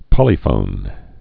(pŏlē-fōn)